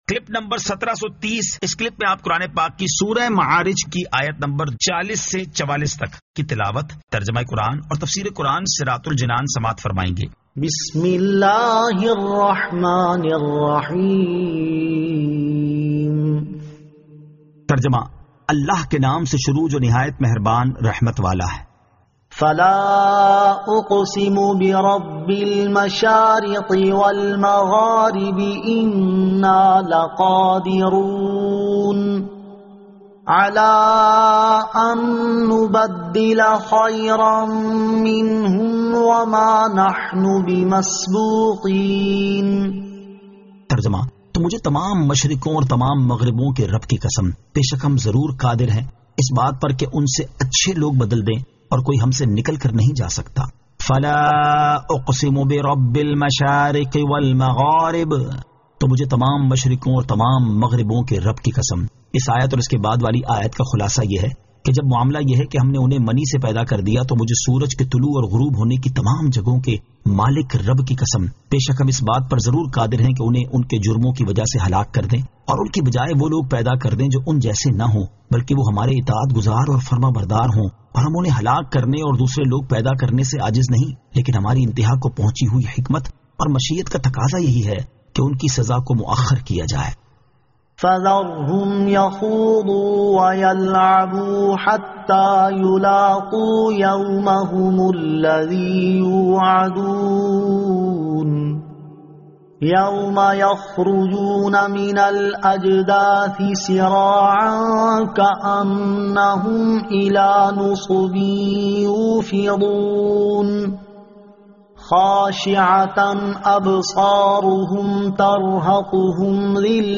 Surah Al-Ma'arij 40 To 44 Tilawat , Tarjama , Tafseer